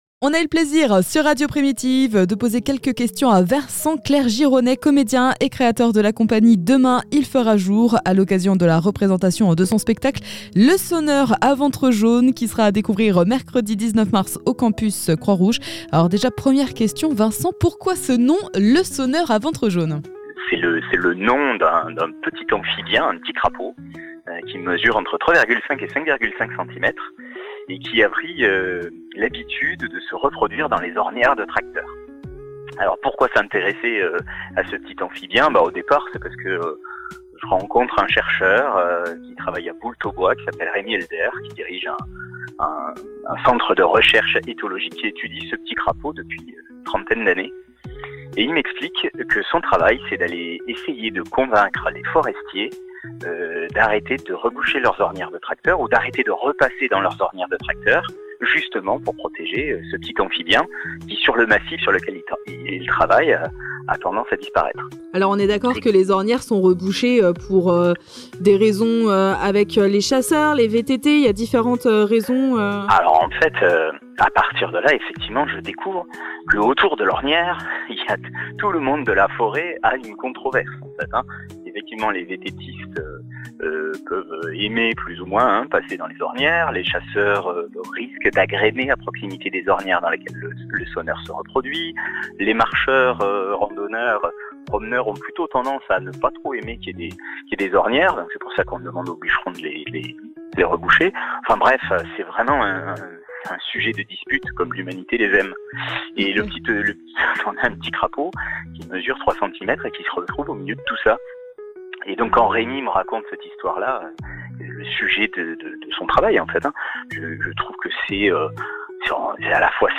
Par la compagnie "Demain il fera jour" (21:05)